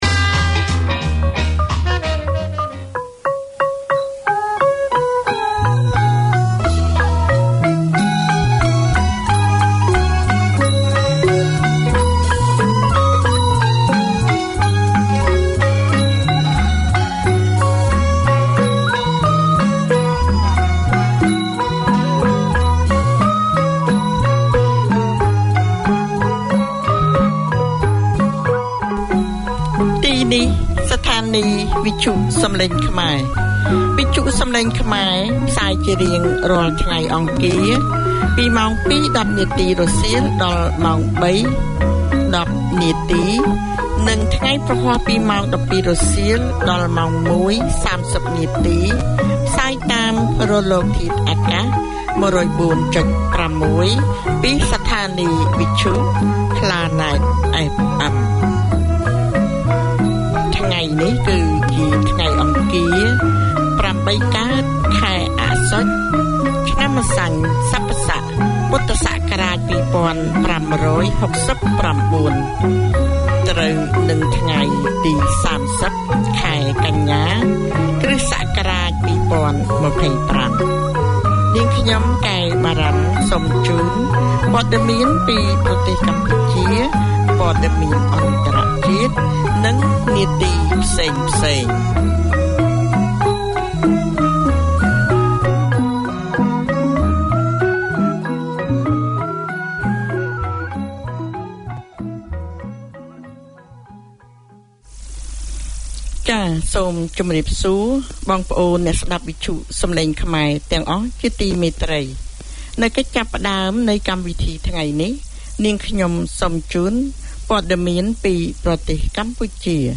Presented by an experienced English teacher who understands the needs of his fellow migrants, these 30 minute lessons cover all aspects of English including Pronunciation, Speaking, Listening, Reading and Writing. Hear how to improve English for general and workplace communication, social interactions, job interviews, IELTS and academic writing, along with interviews with migrants and English experts.